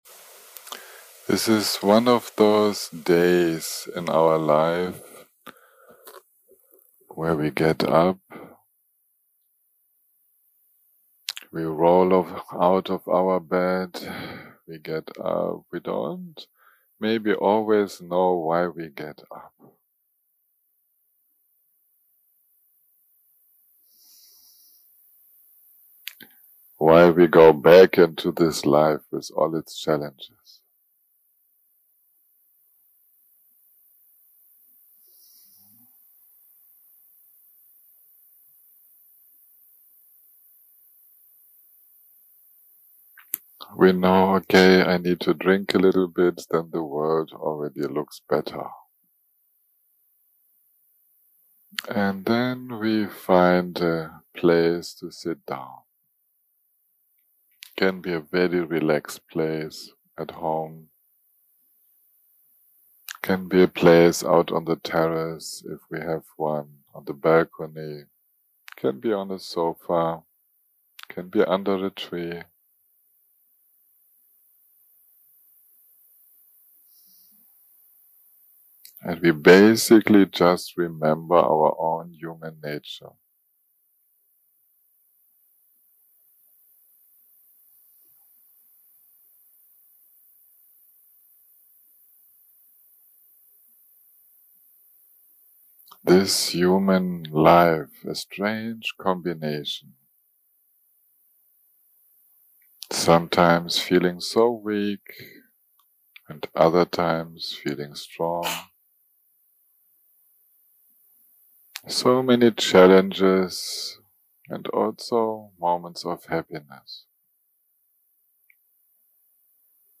day 8 - recording 26 - Early Morning - Guided Meditation - The Nature of the Mind 0:00 0:00 סוג ההקלטה: Dharma type: Guided meditation שפת ההקלטה: Dharma talk language: English